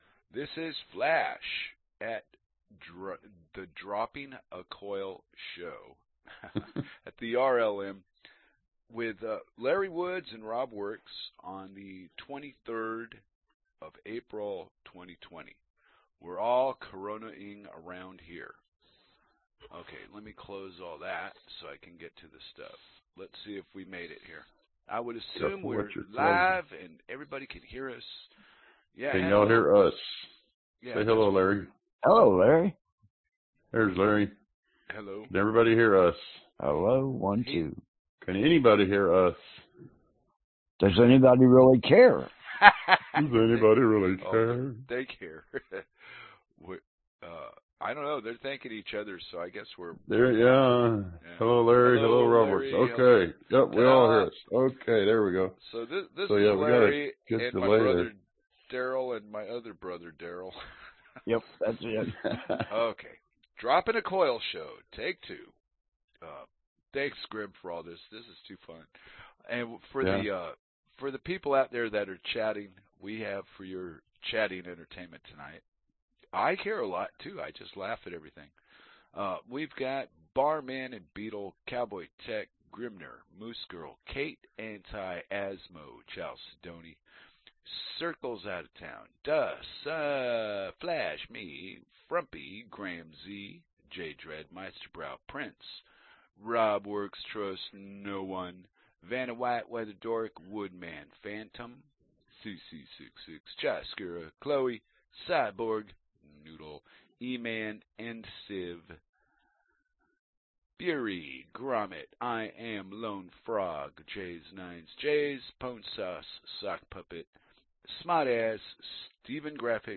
Album Dropping a Coil Genre Talk